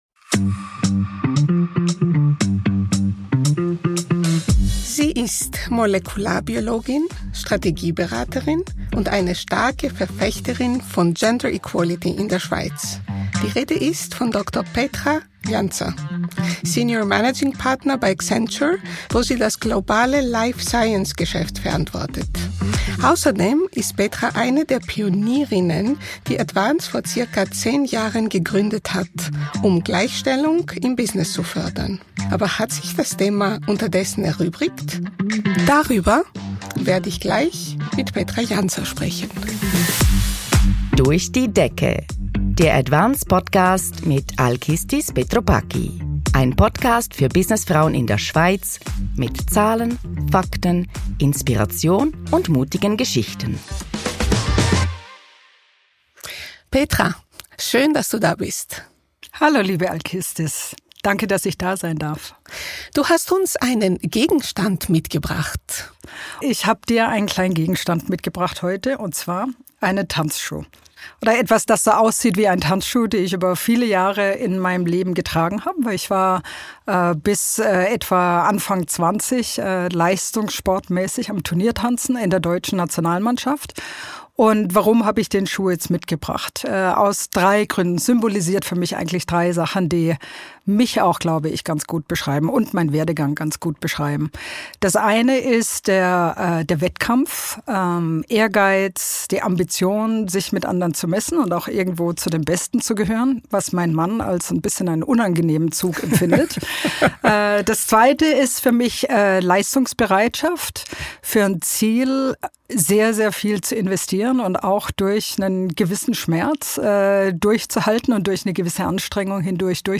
Ein Gespräch über Karrierewege jenseits der Norm, strukturelle Barrieren und warum Gleichstellung wirtschaftlich notwendig ist.